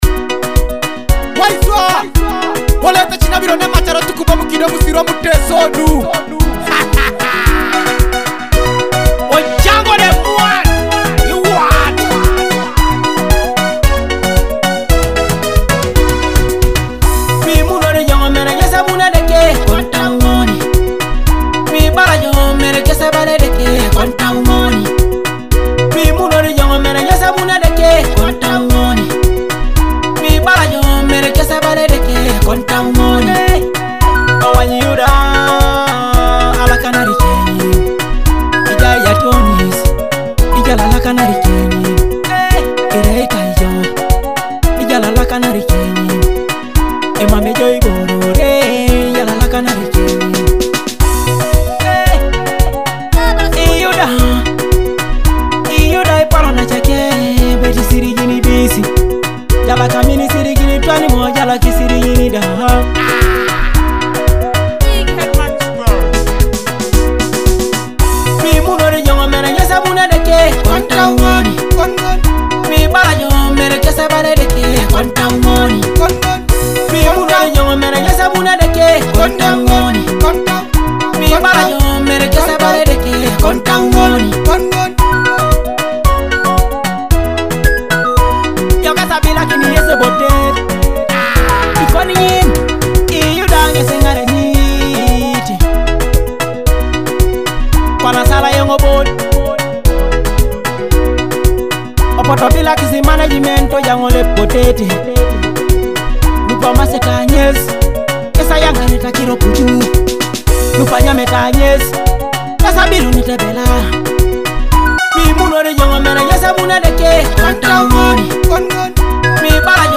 Reflective Gospel, Dancehall